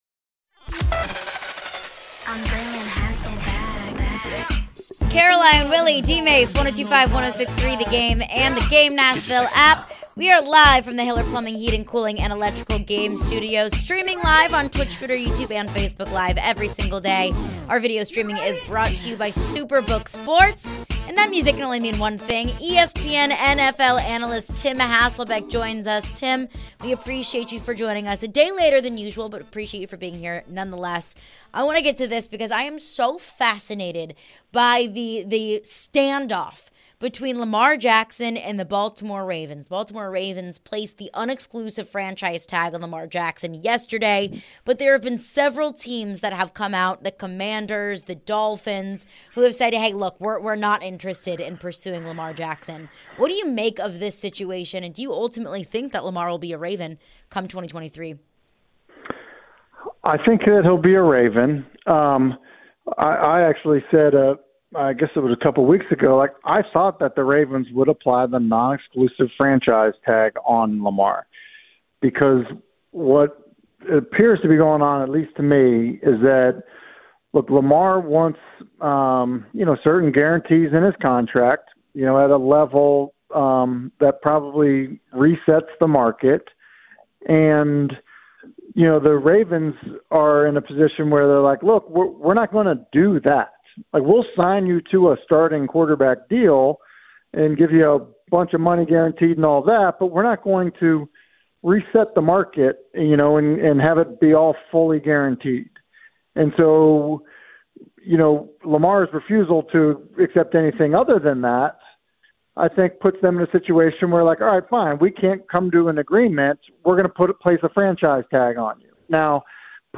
Tim Hasselbeck Interview (3-8-23)